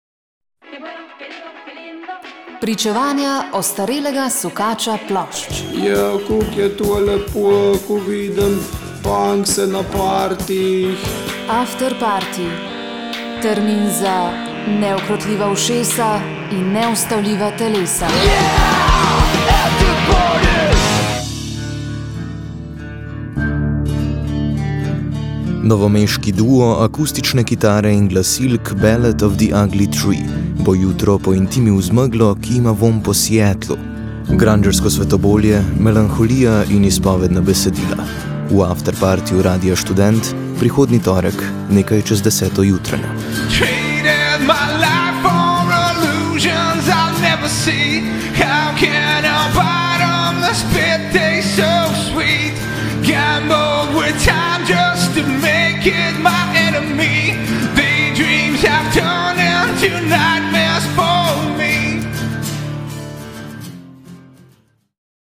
Grungerska akustična melanholija novomeškega dvojca.